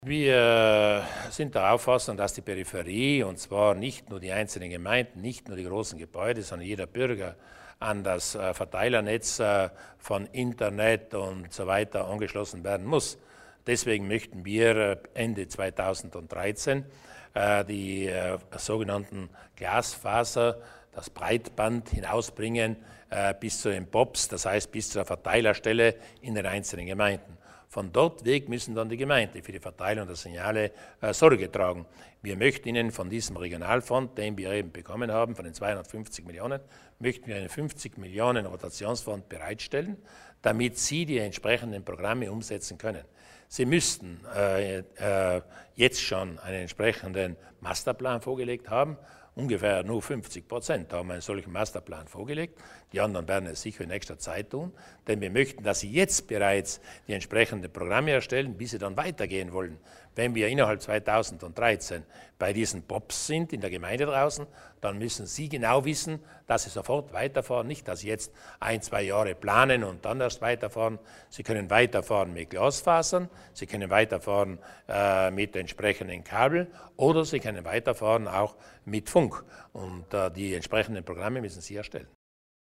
Landeshauptmann Durnwalder erläutert die Investitionen in die Breitbandtechnik